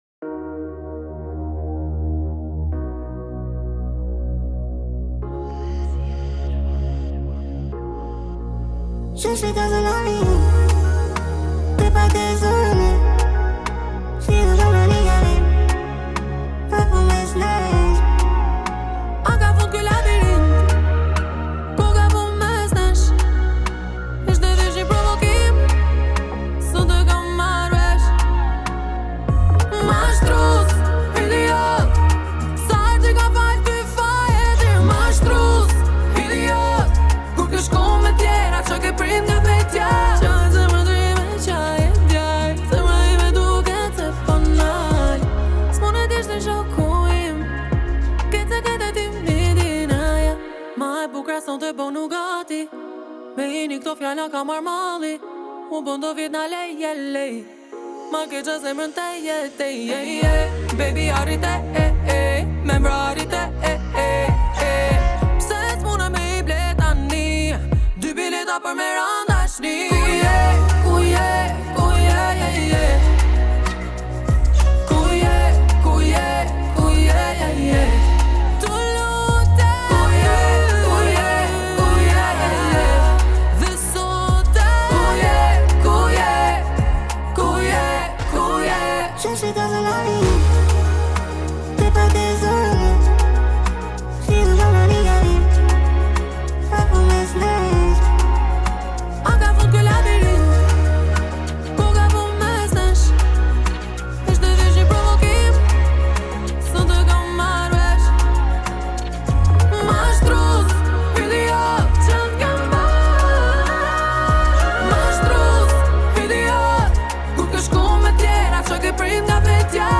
В стиле легкой попсы